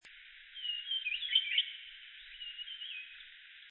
烏線雀鶥 Alcippe brunnea brunnea
南投縣 水里鄉 大觀發電廠
錄音環境 次生林
雄鳥歌聲對唱(一隻近、一隻遠)
Denon Portable IC Recorder 型號 DN-F20R 收音: 廠牌 Sennheiser 型號 ME 67